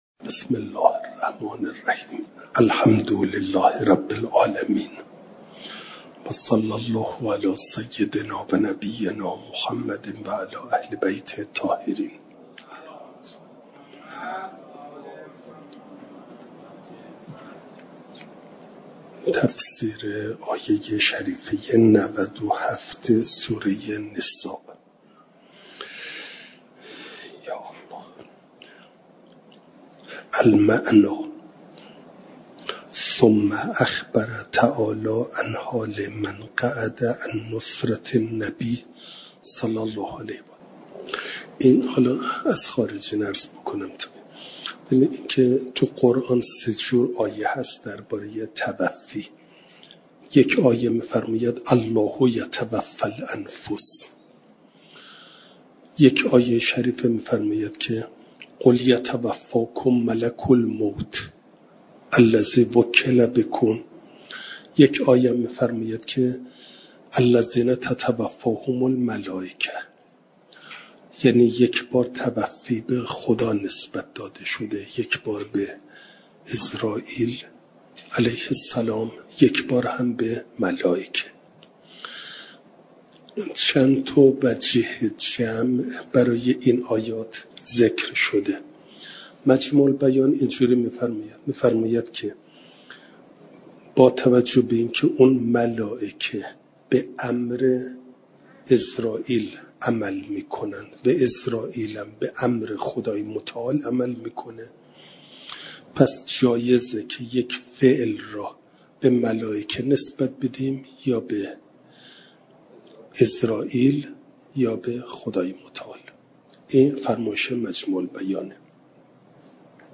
جلسه سیصد و هشتاد و پنجم درس تفسیر مجمع البیان